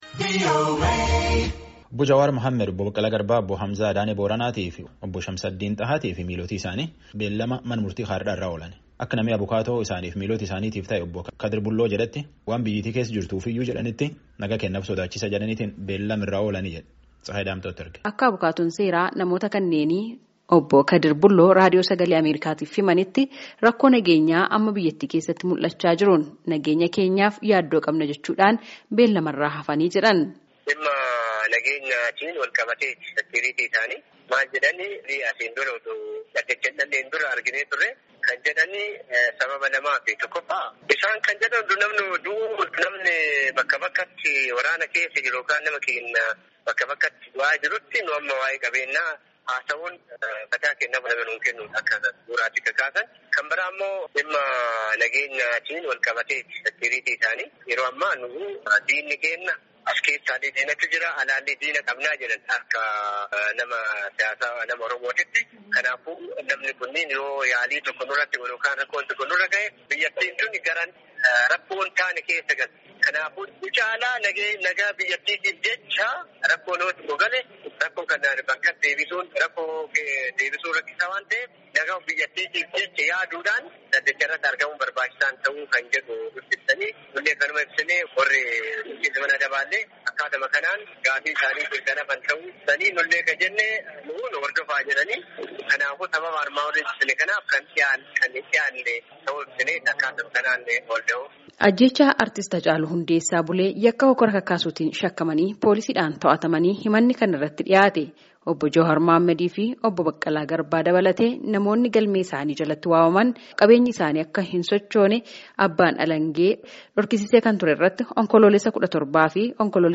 Gabaasaa guutuu caqasaa